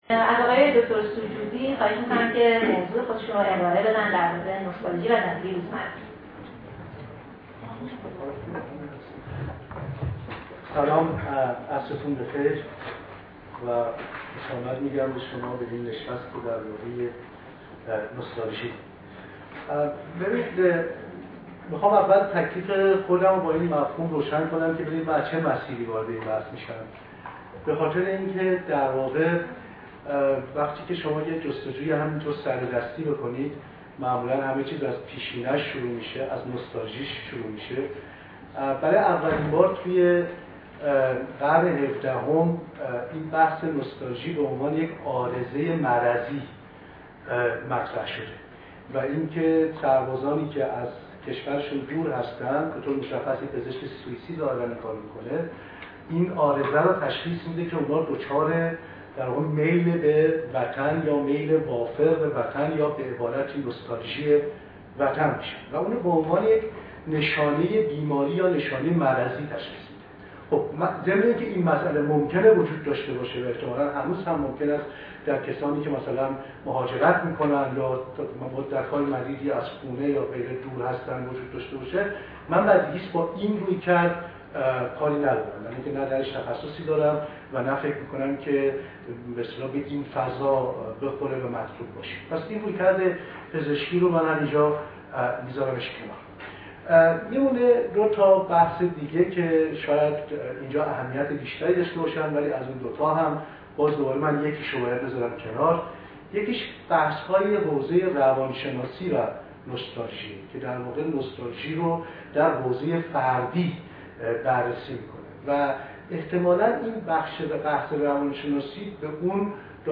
سخنرانی
در خانهی هنرمندان ایران برگزار شد